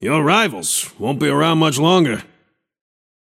Shopkeeper voice line - Your rivals… won’t be around much longer.